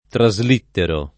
traslitterare v. (ling.); traslittero [